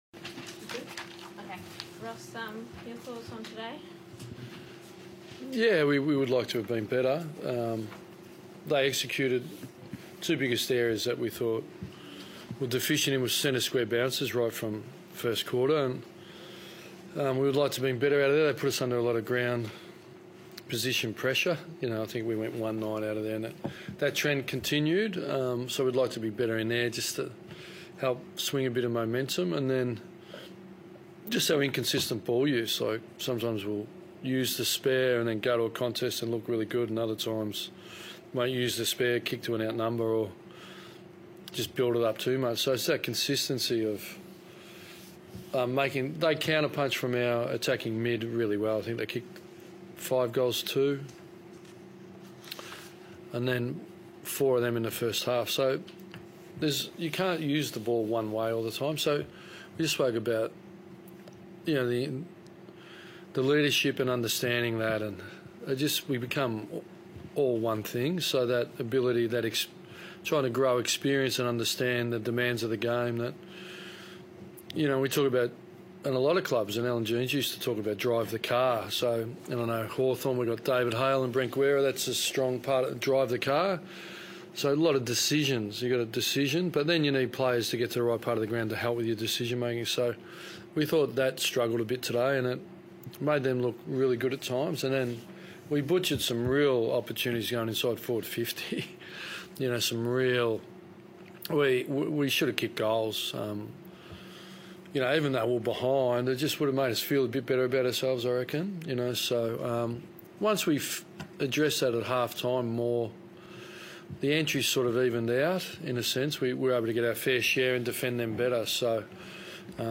Ross Lyon spoke to the media following the loss against Hawthorn in round 19.